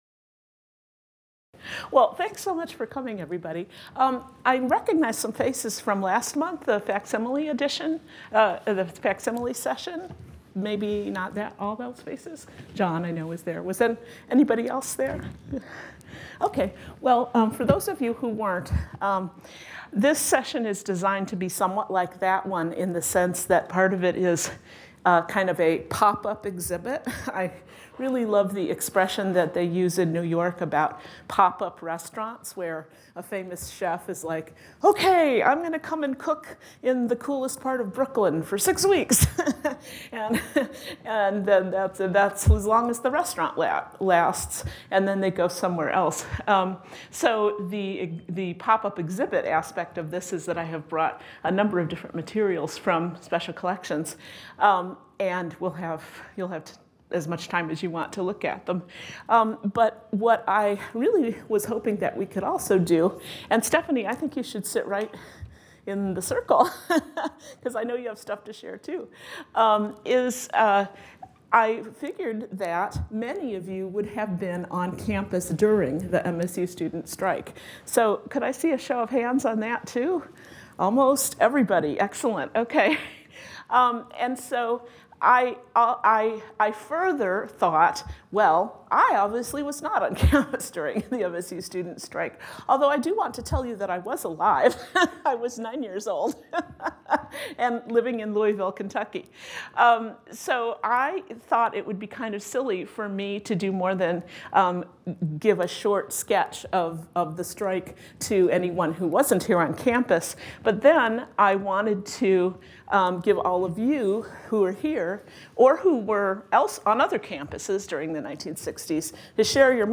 Discussion of the era of student unrest, activism and strikes on the Michigan State University campus
Participants talk about where they were during the protests, what they witnessed, and their level of participation. A former military science professor recalls interim president Walter Adams and his interaction with the students at Demonstration Hall and a discussion ensues about the differences between Adams and President Clifton Wharton.